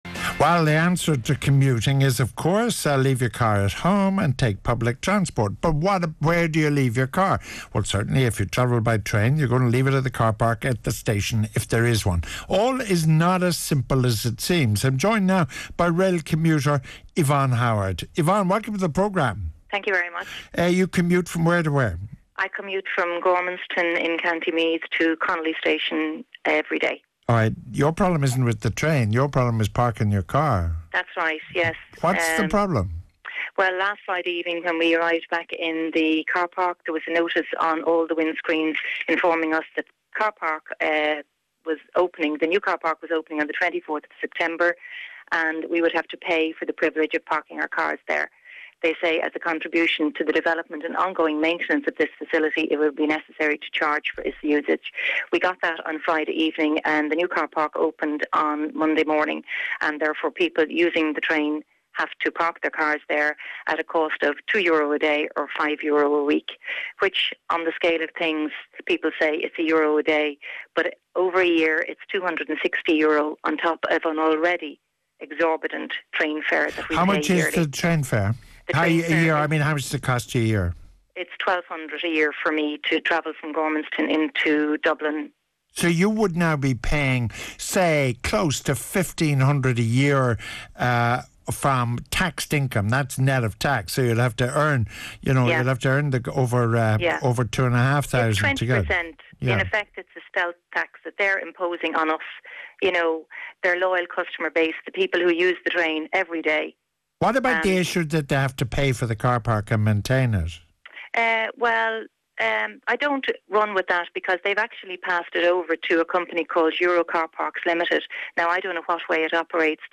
Discussion of the new Irish Rail policy of charging for parking at suburban stations, George Hook is the host, a angry commuter from Gormanston